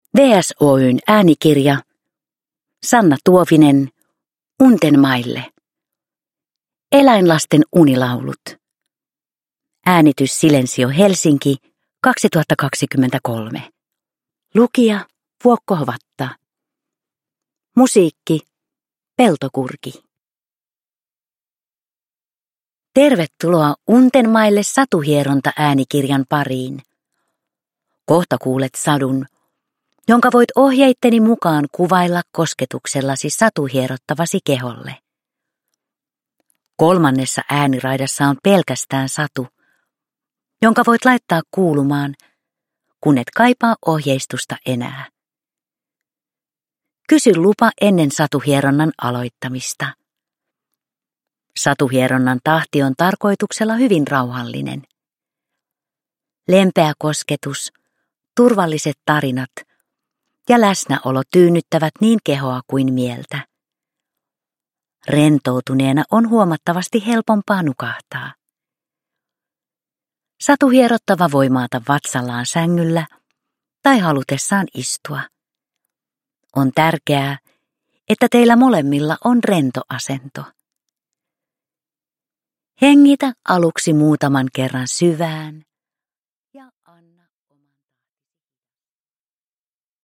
Iltahetki täyttyy puron solinasta, metsän huminasta, lintujen laulusta...
Untenmaille äänikirjat sisältävät teoksia varten sävelletyn rauhoittavan ja elämyksellisen musiikillisen äänimaiseman.